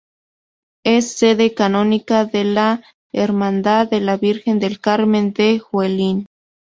Pronúnciase como (IPA) /kaˈnonika/